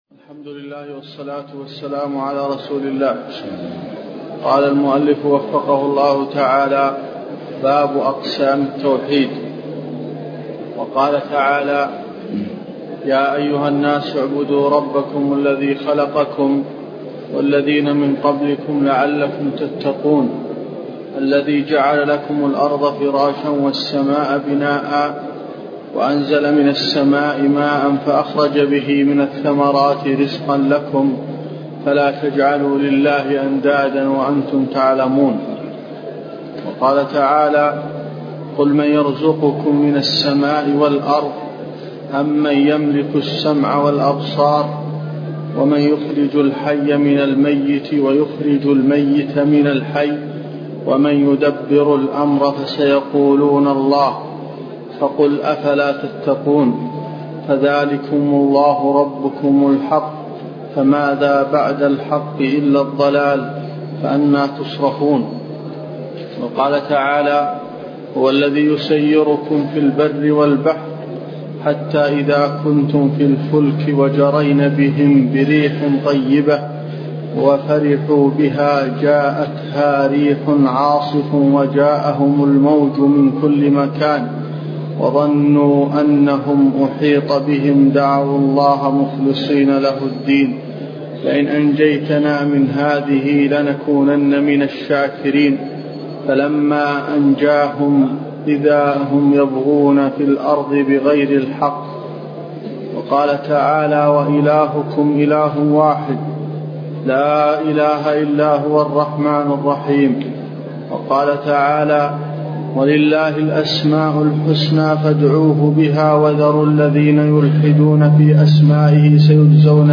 تفاصيل المادة عنوان المادة الدرس (24) شرح المنهج الصحيح تاريخ التحميل الأحد 15 يناير 2023 مـ حجم المادة 33.06 ميجا بايت عدد الزيارات 271 زيارة عدد مرات الحفظ 102 مرة إستماع المادة حفظ المادة اضف تعليقك أرسل لصديق